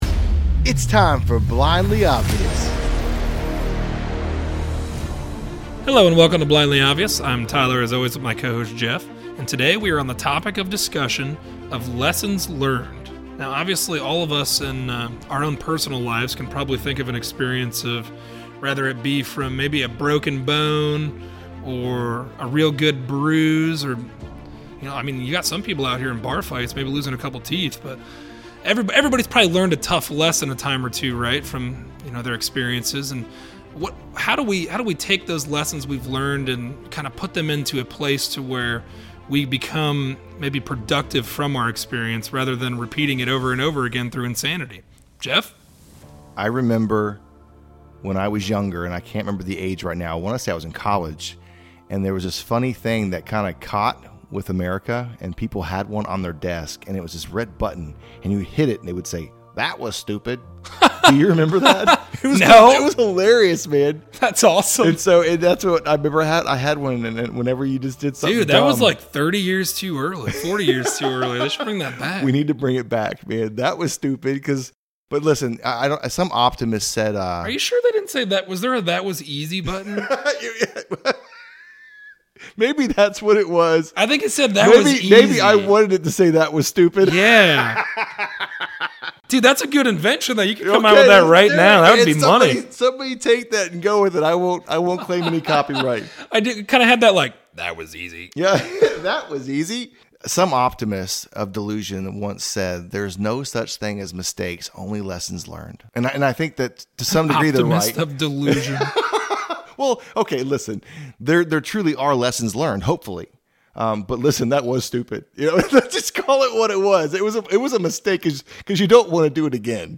A conversation on lessons learned. Disasters happen… can we learn anything from them?